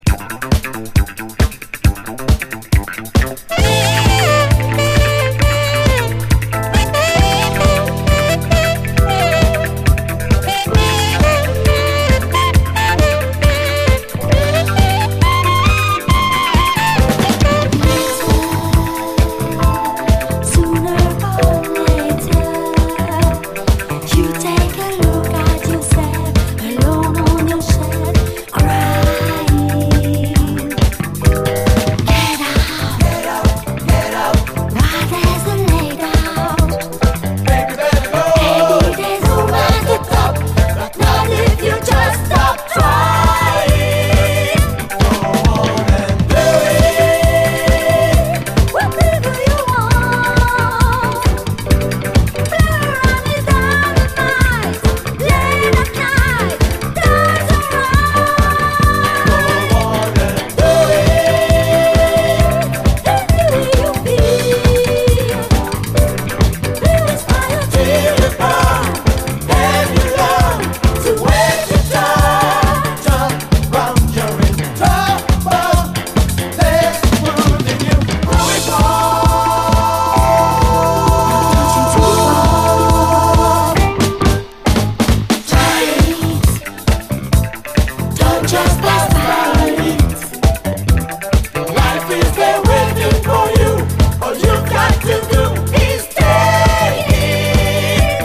SOUL, DISCO
ベルギー産のセクシー・ユーロ・ディスコ12インチ！